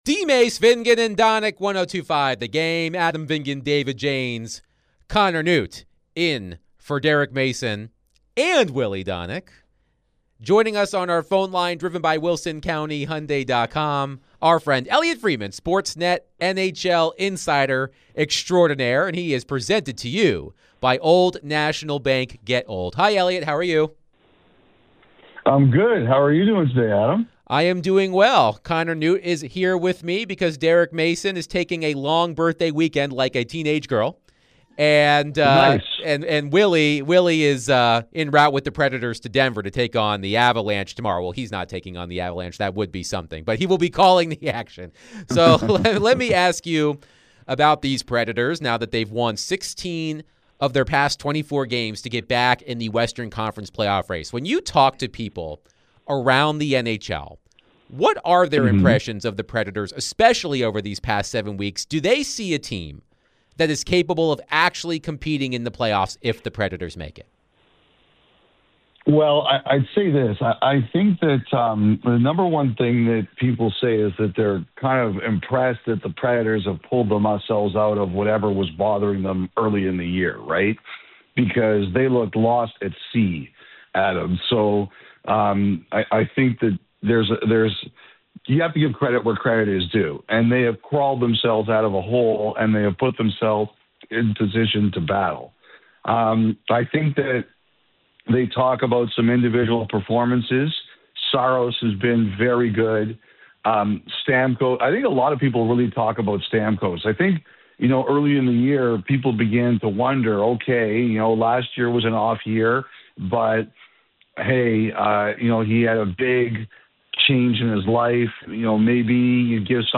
NHL Insider Elliotte Friedman joined DVD to discuss the Nashville Predators so far, NHL as a whole, and more